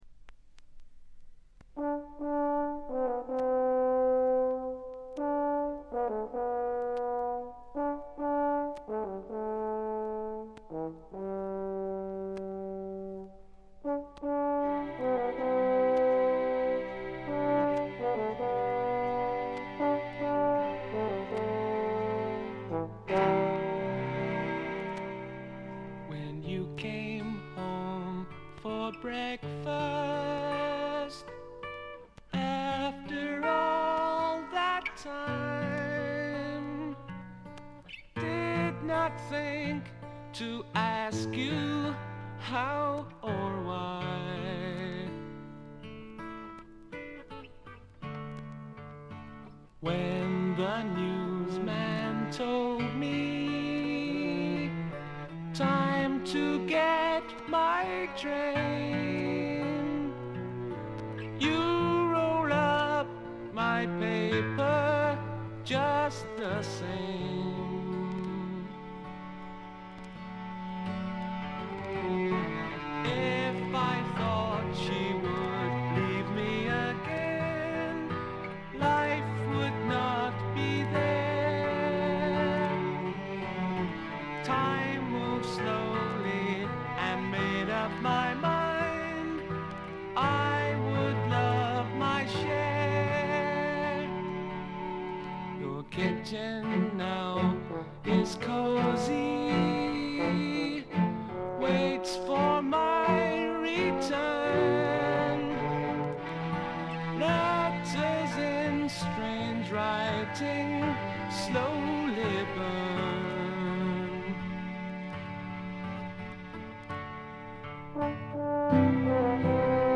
B5冒頭で周回気味のノイズが出ますがごく軽いものです。
英国の男性デュオ
フォーク・ロックを基調に栄光の英国ポップのエッセンスをたっぷりふりかけた音作りです。
超英国的な陰影に満ちたしめっぽさと、切ないメロディの甘酸っぱいサウンド。
試聴曲は現品からの取り込み音源です。